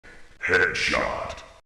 (Category:Killsounds {{AudioTF2}})
CopyrightThis is an audio clip from the game Team Fortress 2.